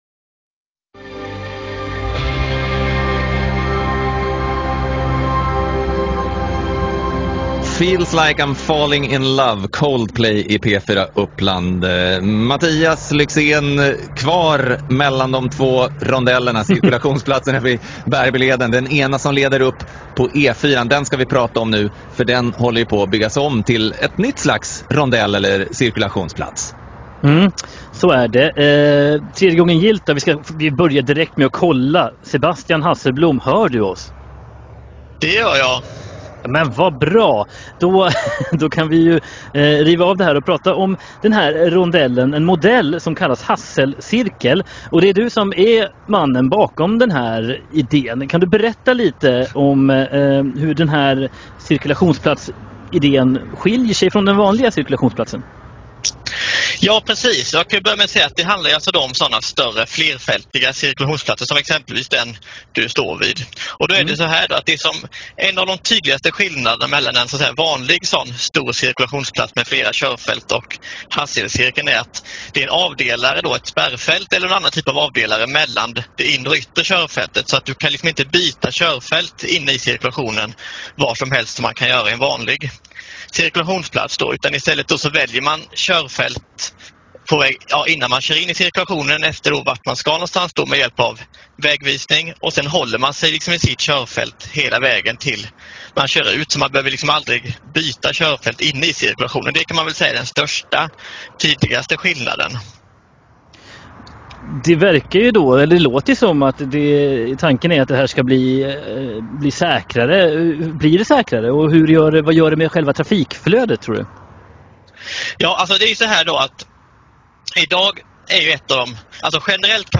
Nedsparad version).